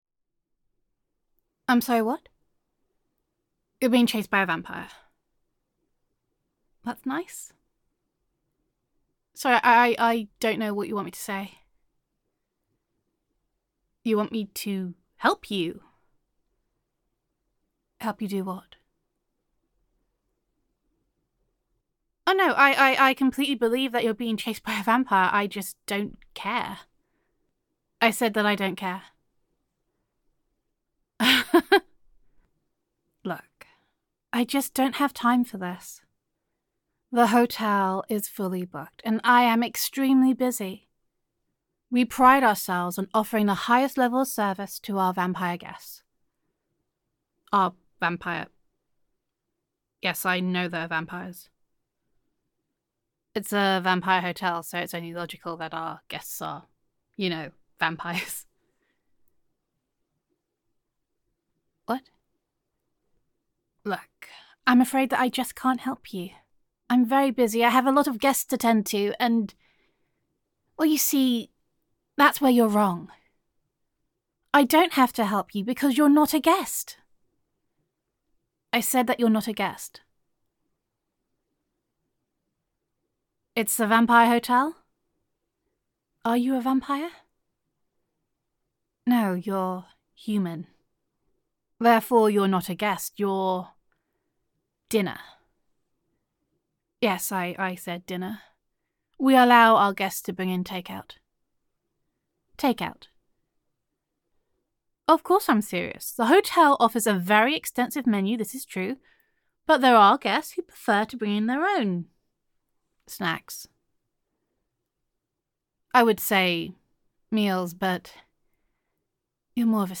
[F4A] This Hotel Sucks [Vampire Hotel][Take Out][Gender Neutral][When You Are Being Chased by a Vampire You Would Think the Concierge Would Be More Helpful]